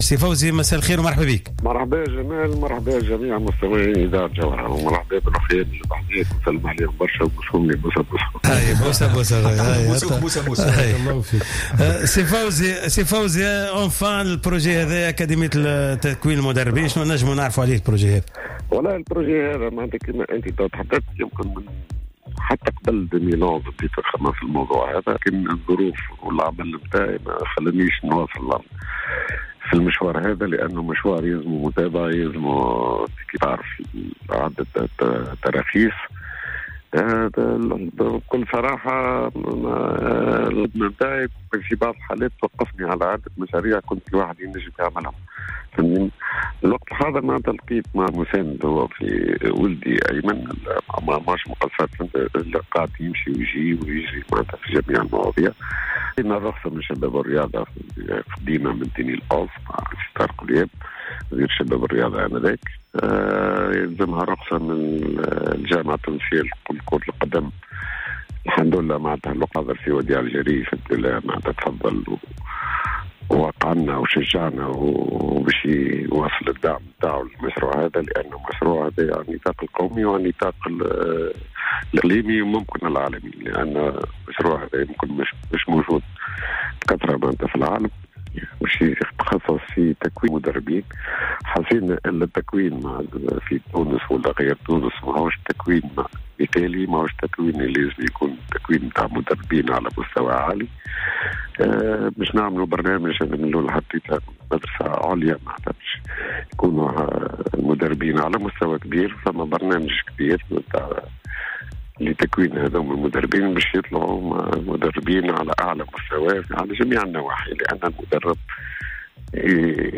تحدث مدرب النجم الساحلي فوزي البنزرتي خلال مداخلة في برنامج "cartes sur table" على جوهرة أف أم حول مشروعه الجديد الذي يتمثل في إحداث أكاديمية للمدربين .